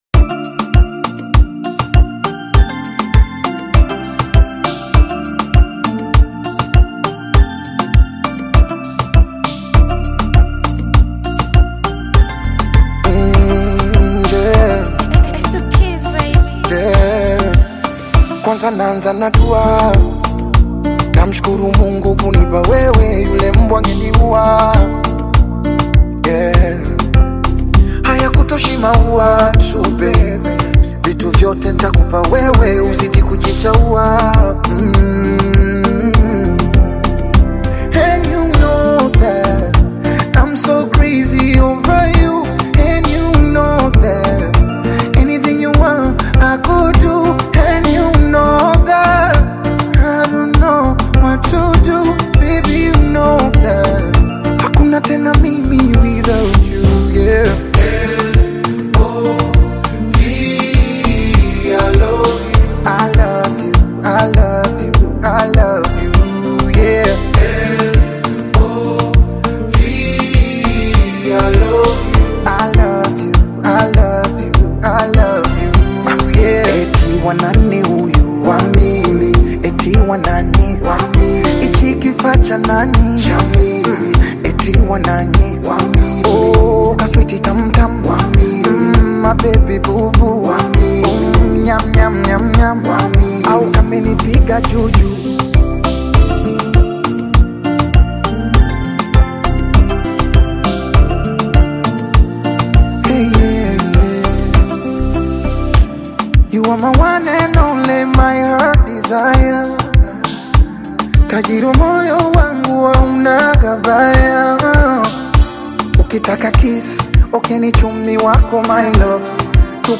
heartfelt Afrobeats/R&B-infused Bongo Flava single
smooth, soulful melodies
Genre: Afrobeat